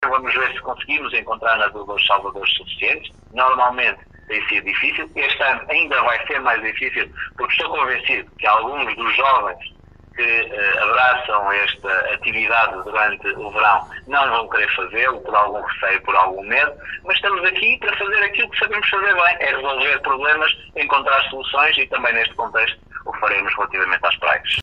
O presidente da Câmara Municipal de Caminha disse hoje temer o pior se o receio causado pelo surto do novo coronavírus reduzir ainda mais o número de candidatos a nadadores-salvadores necessário para as praias com Bandeira Azul.